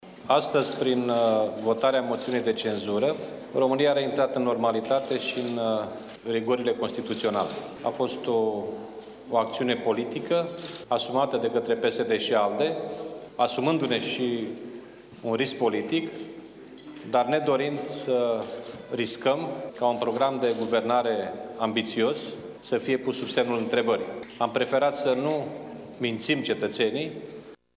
Dragnea a recunoscut că moțiunea de cenzură a presupus un anumit risc politic, dar, care a fost asumat de coaliția de guvernare: